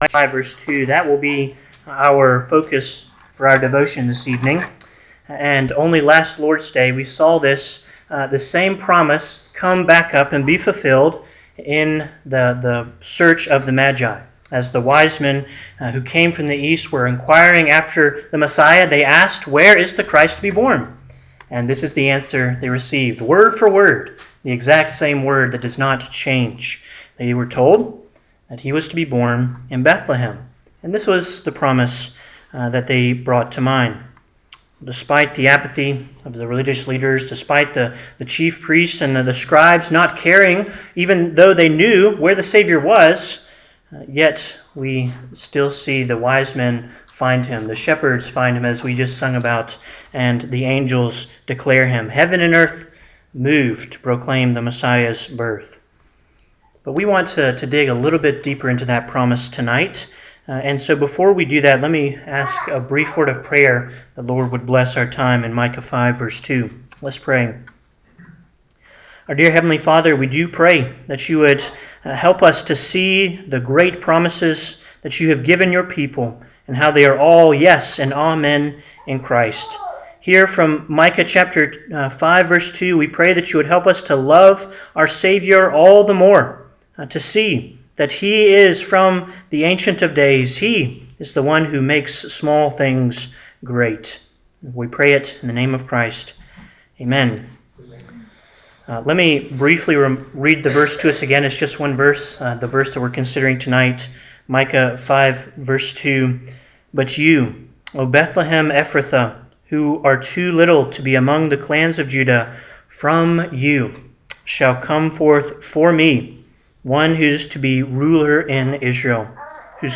Christmas Eve address 12-24-25